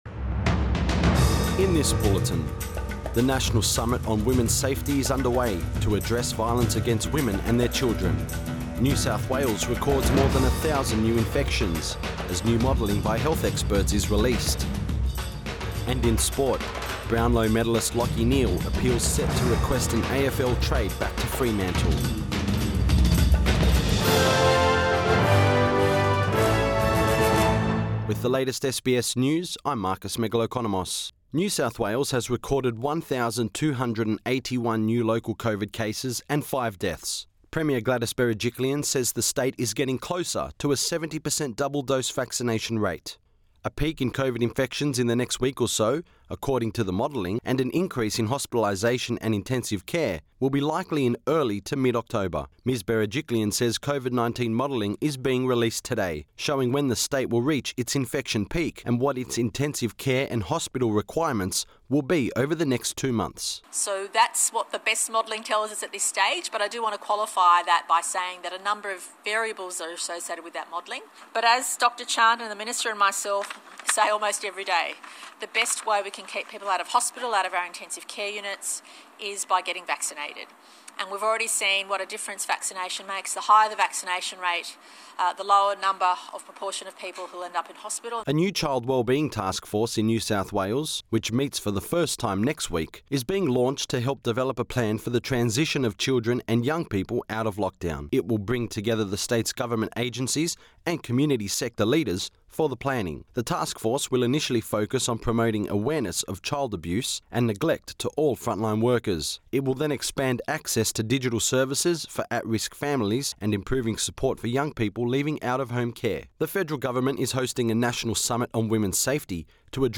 Midday bulletin 6 September 2021